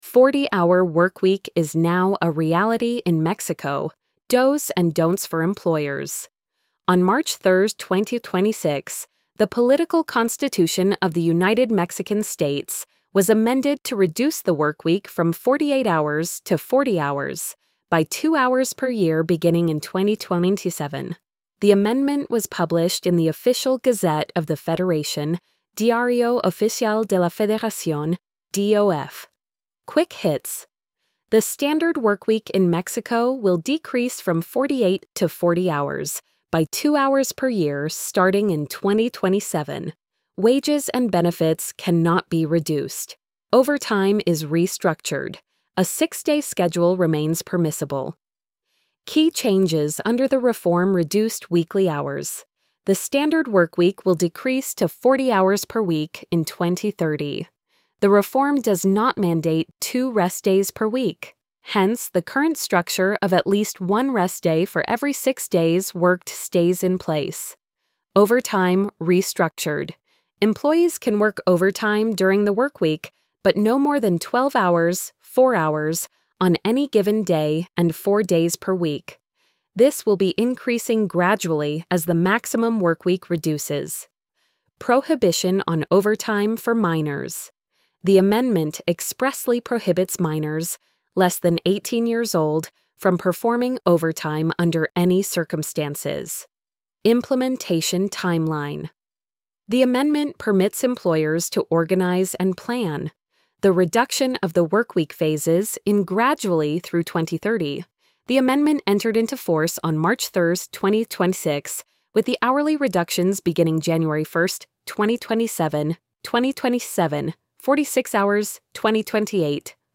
post-86240-tts.mp3